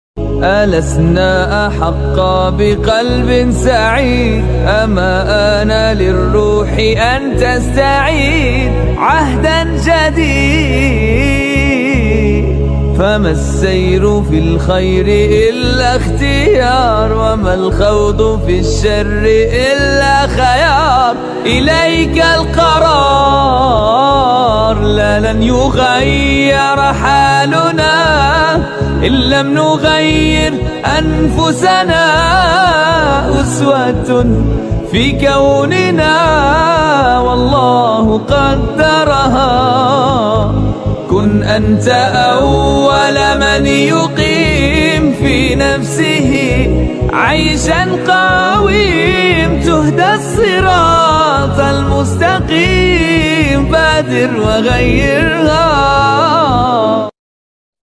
انشودة حلوة جزاك الله خيرا